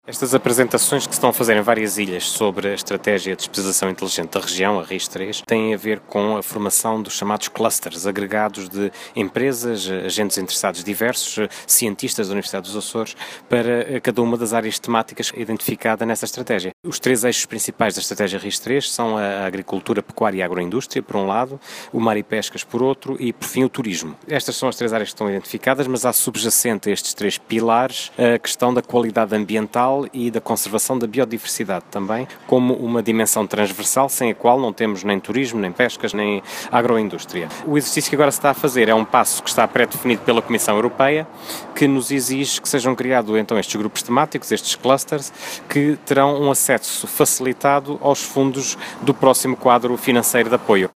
Fausto Brito e Abreu falava na abertura de um seminário sobre a criação de clusters nos Açores, onde salientou que a ligação da Ciência ao meio empresarial é “uma prioridade” do Executivo.